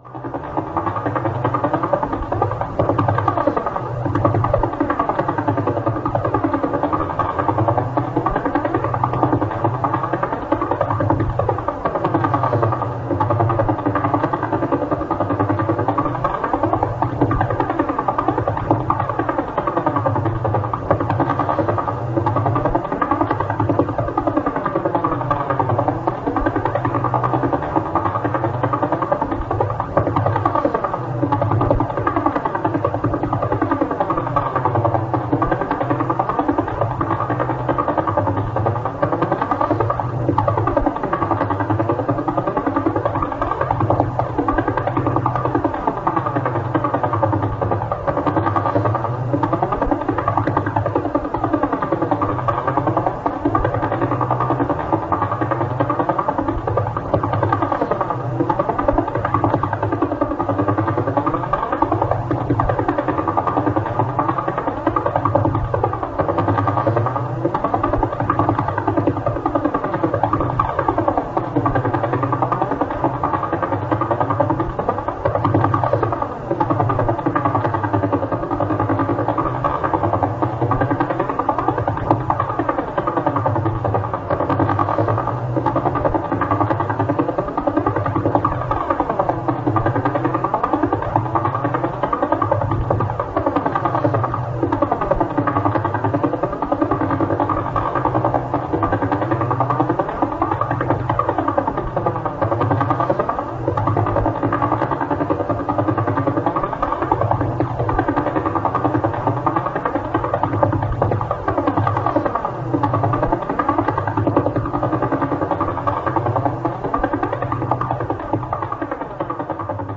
Эти уникальные аудиодорожки, преобразованные из электромагнитных колебаний, идеально подходят для создания атмосферной музыки, монтажа видеороликов, научной работы или глубокой релаксации.
Звук странички пульсара Вела